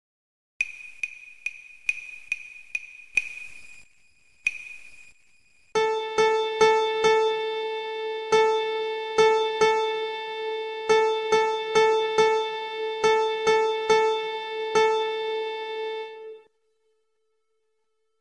Each question will be played five times.
2) 6 8 Time, four measures long
Uses dotted quarter, quarter, and eighth notes.